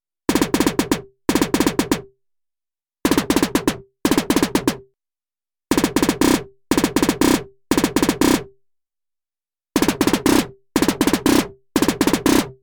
I’ve made a quick example on the Syntakt: with, without, with, without.
By default, it sounds like a one-shot sample. With adding random LFO(s) to selected parameters, the effect is gone and it sounds like one would a synthesizer or a real snare expect to sound (to sound real, it needs additionally velocity variation).